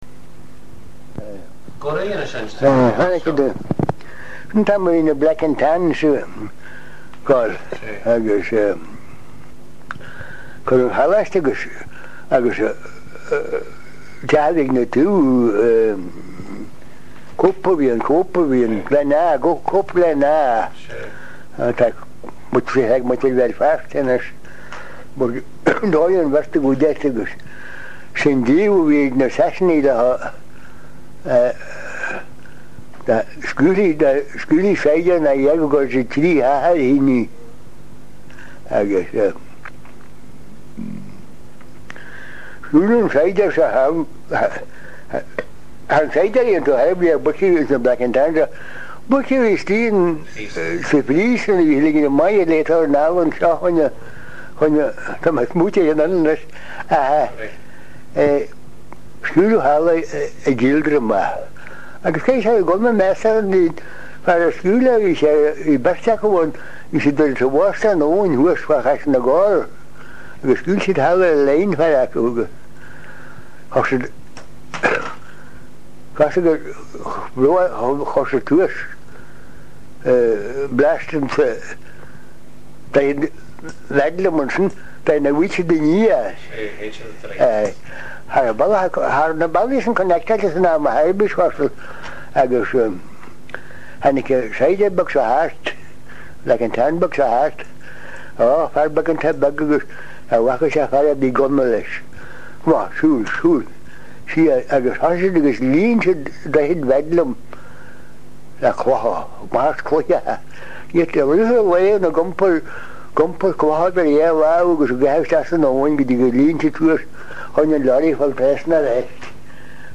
Sonraí an agallaimh/na n-agallamh